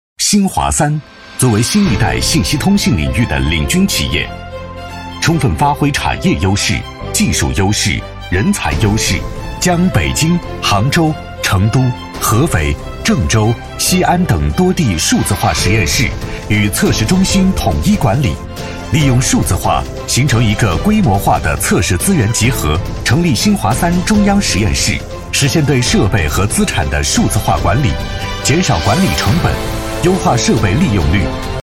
A男15号
【企业】新华三（朝气 干脆）